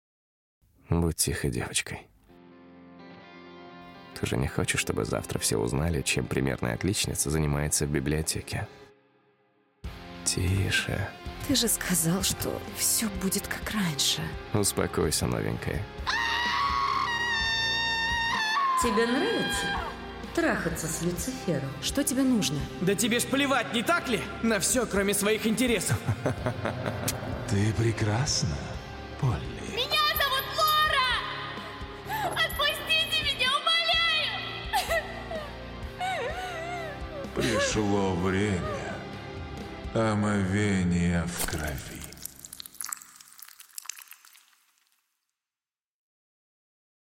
Романтика, триллер. Аудиокнига (аудиоспектакль). Многоголосая версия.
Trailer-Heaven-Secret-College.mp3